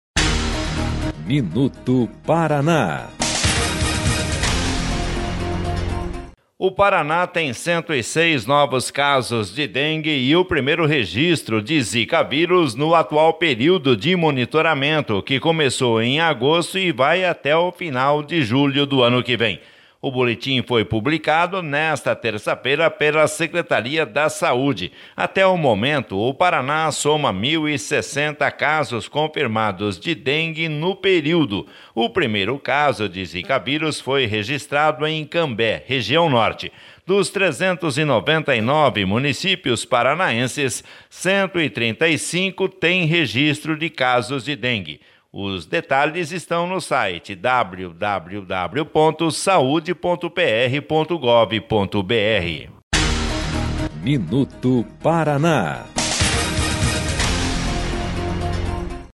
MINUTO PARANÁ - BOLETIM ATUALIZADO DA DENGUE